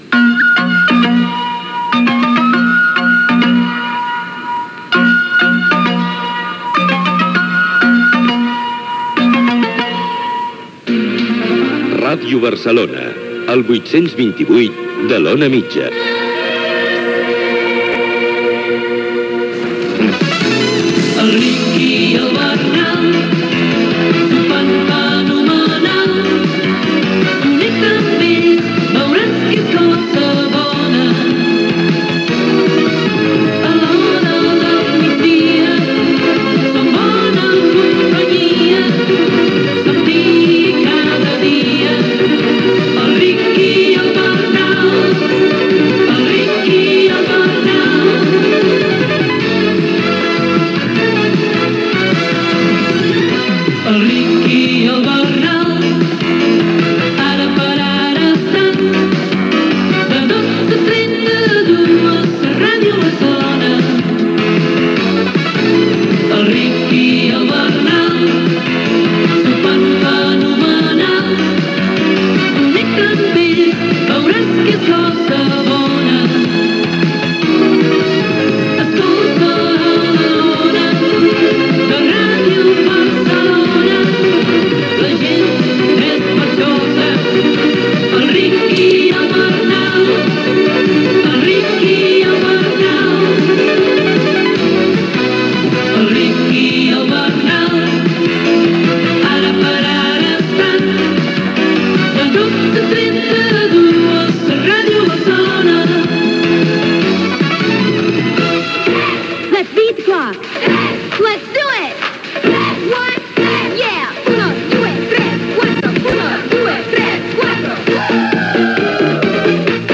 Identificció de la ràdio a l'ona mitjana, careta cantada del programa, tema musical, diàleg inicial dels presentadors sobre el partit de lliga entre l'Espanyol i el Futbol Club Barcelona
Entreteniment